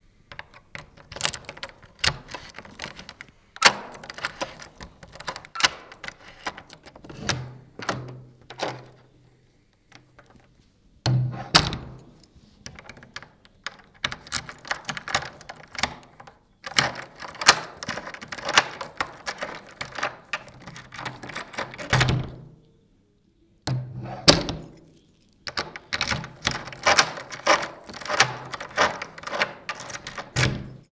Звуки ключей: поворот ключа в замочной скважине